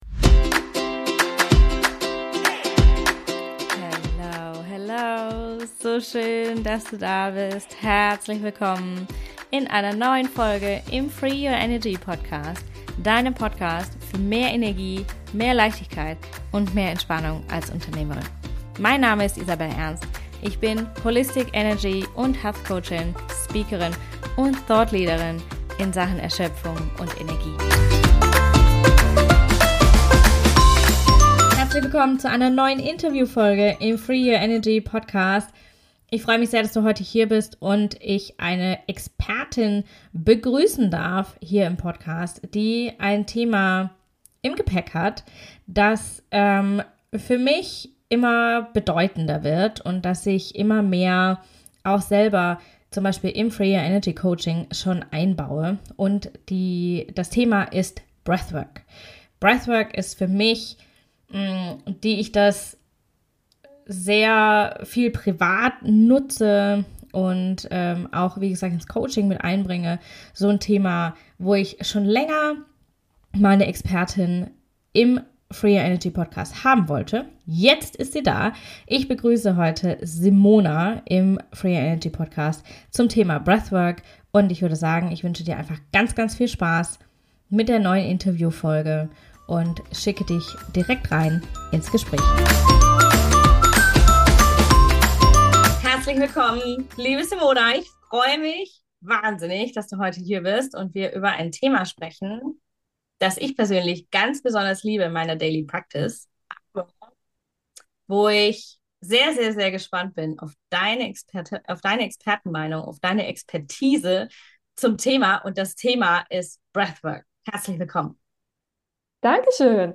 160 - Mit Breathwork in die innere Mitte | Interview